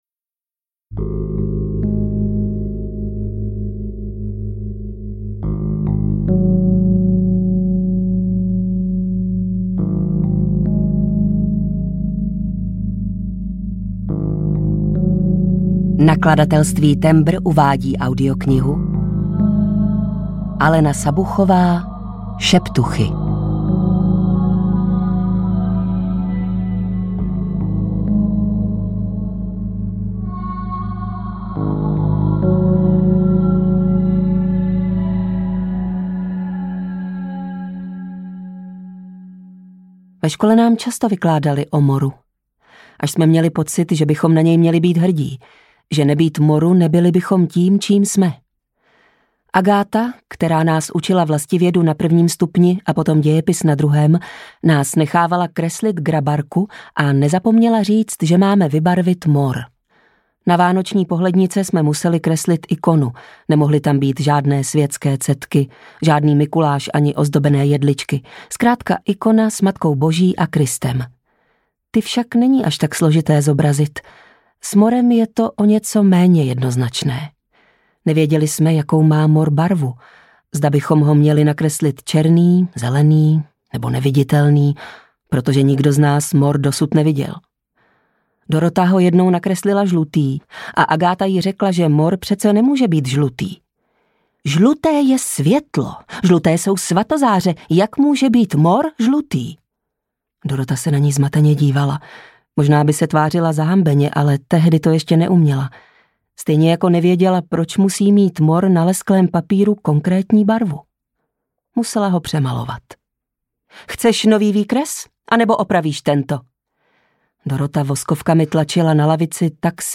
Šeptuchy audiokniha
Ukázka z knihy